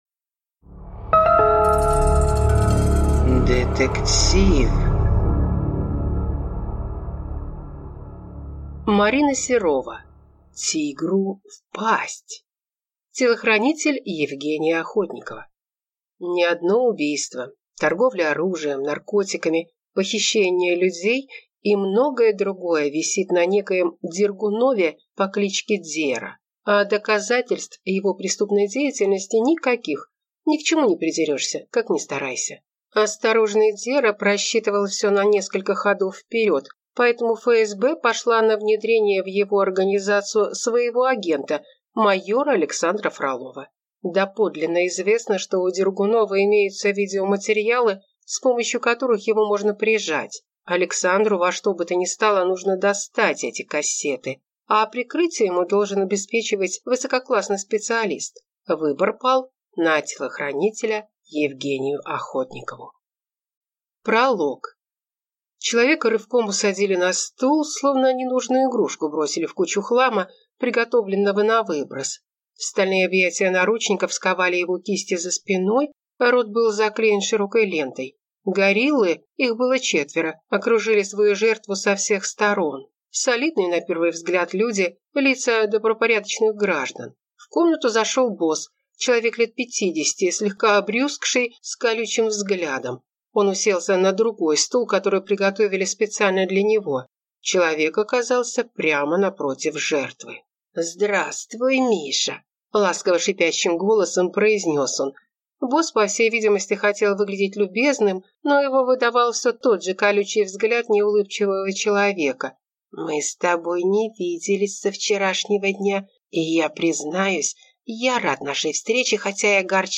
Аудиокнига Тигру в пасть | Библиотека аудиокниг